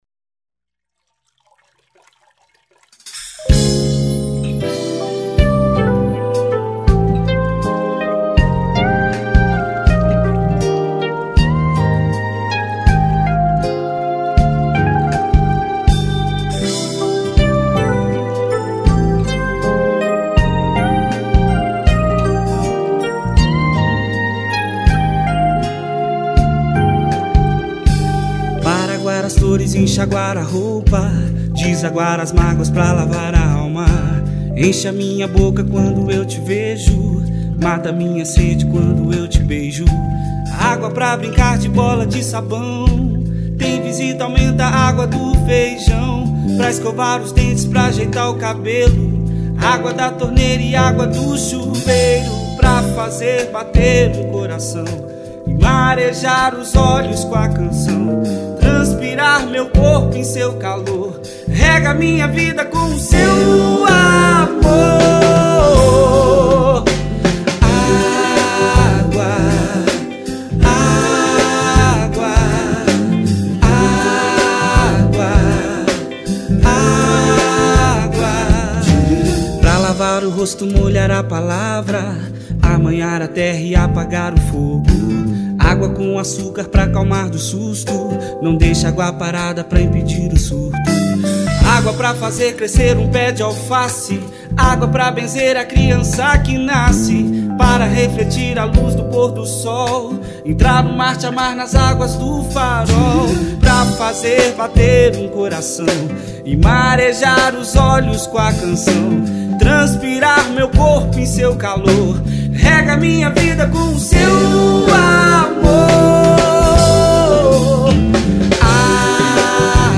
EstiloMPB